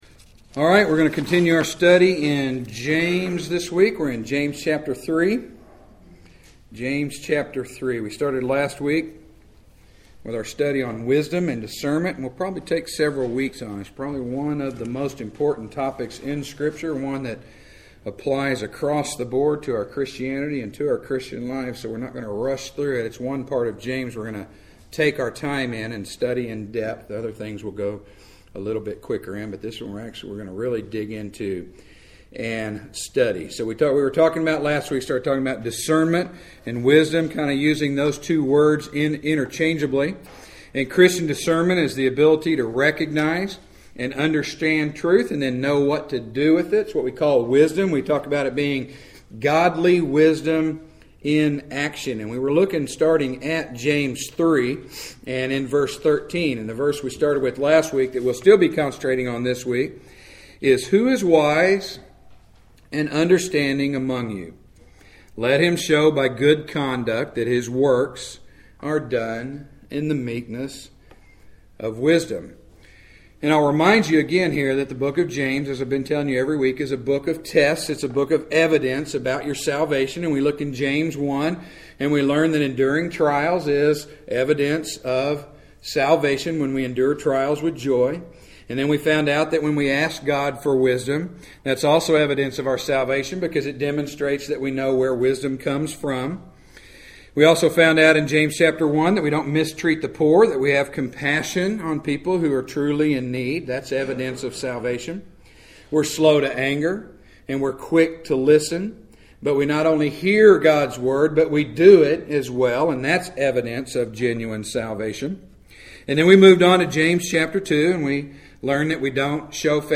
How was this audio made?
Preached at Straightway Bible Church on April 21st, 2013.